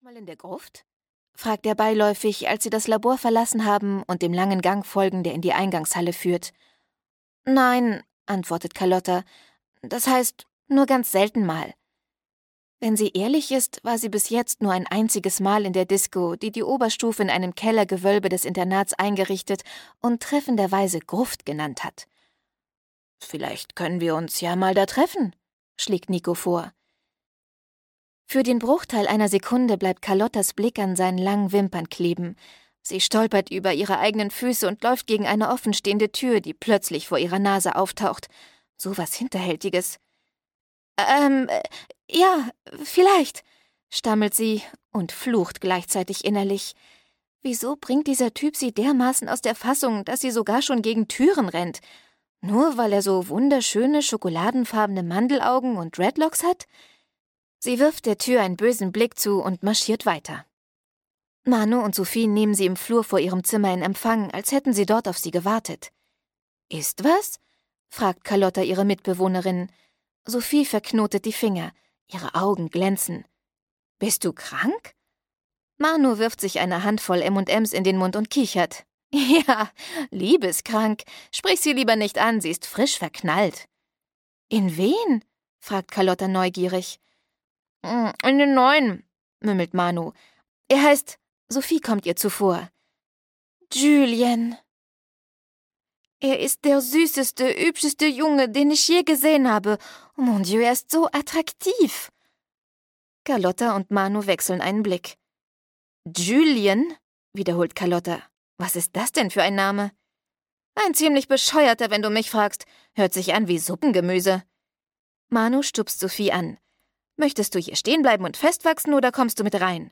Carlotta 4: Carlotta - Internat und Prinzenball - Dagmar Hoßfeld - Hörbuch